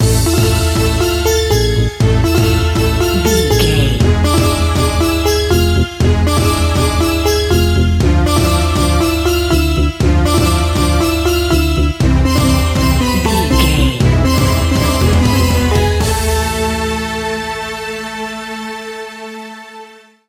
Aeolian/Minor
World Music
percussion